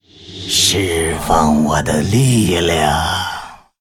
行为语音下载
攻击